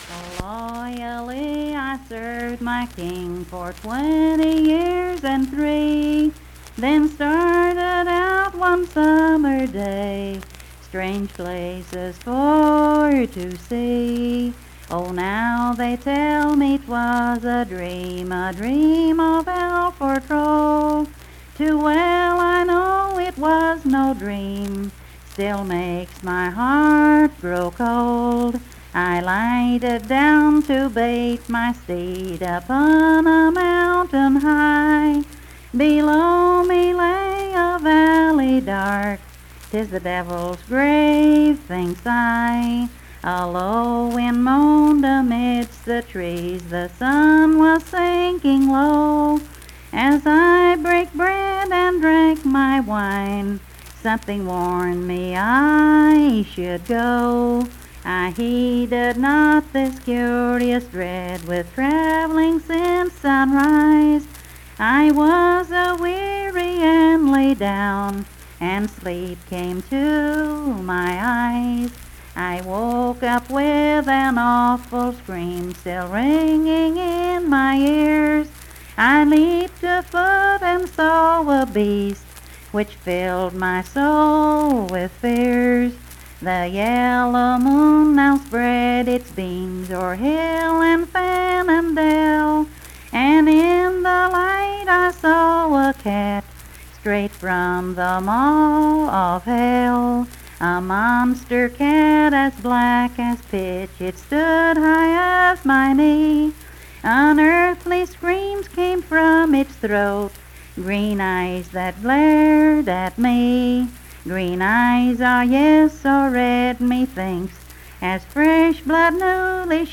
Unaccompanied vocal music
Verse-refrain 44(4).
Key Topics: Miscellaneous--Musical Performance Media: Voice (sung) Rights: Copyright Not Evaluated Location: Marion County (W. Va.)